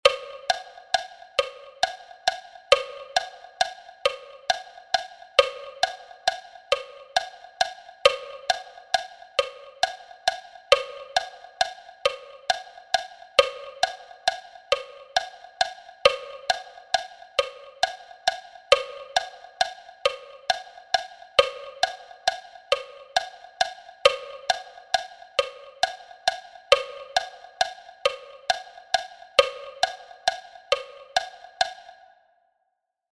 Metronome sound (dotted crotchet at 45 bpm)
metron_6_8_negra_con_puntillo_a_45.mp3